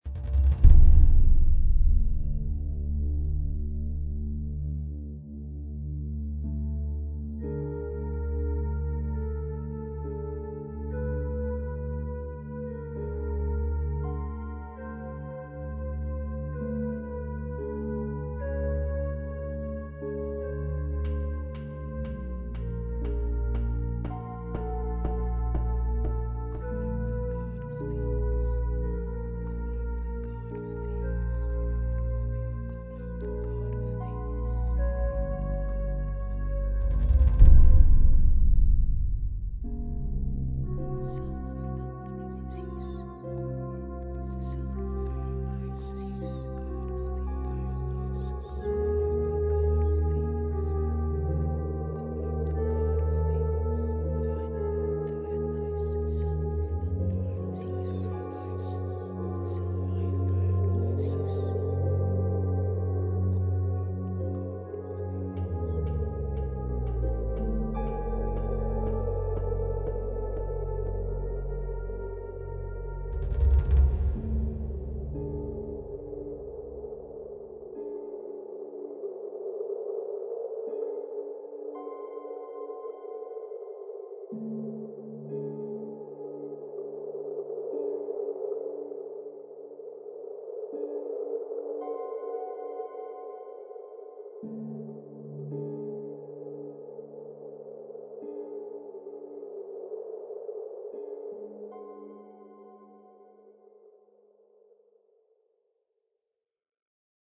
Orchestral & Instrumental Composer
Much of the music was written to create an atmosphere, more than it was to convey a melody, and this is especially true of Tiresias' theme and Dionysus.